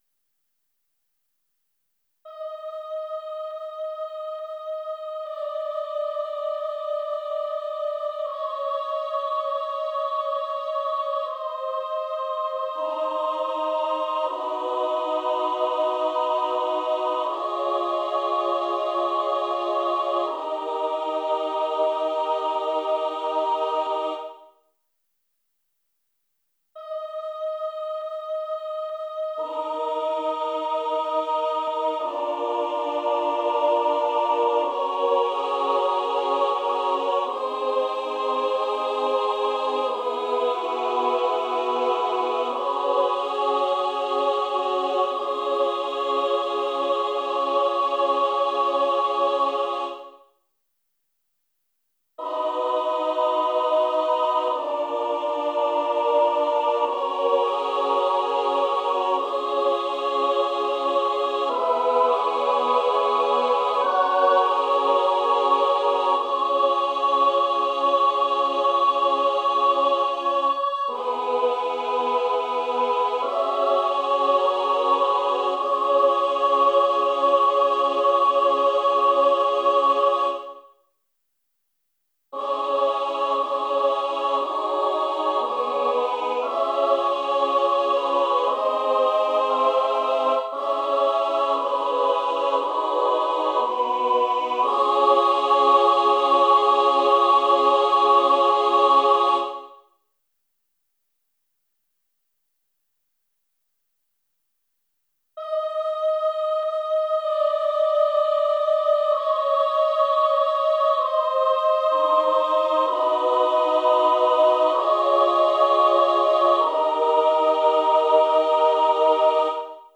Title Newborn Girl (SSAA a capella) Opus # 167 Year 2004 Duration 00:03:05 Self-Rating 3 Description Written in maybe four hours in one of those rare bursts of inspiration. For performance, I imagine the soloists sitting down with a picture album. mp3 download wav download Files: mp3 wav Tags: Choral, A Capella Plays: 1911 Likes: 0